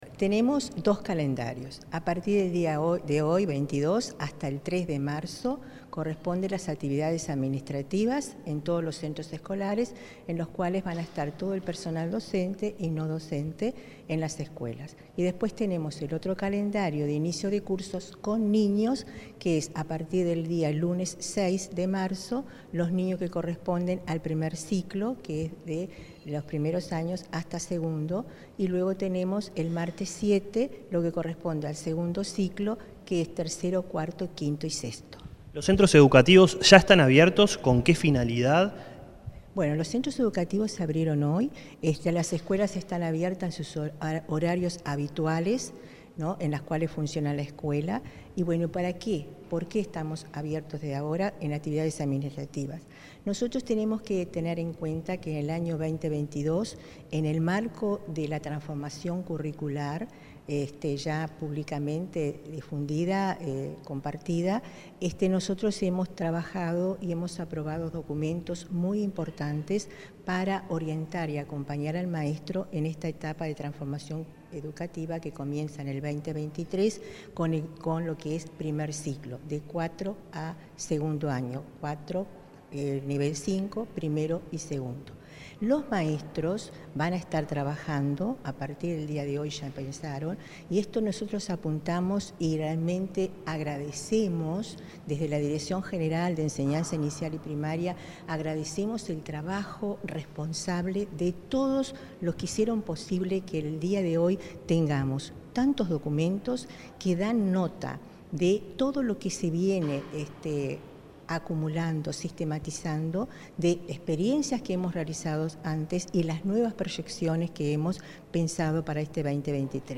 Entrevista a la subdirectora de Educación Inicial y Primaria de ANEP, Olga de las Heras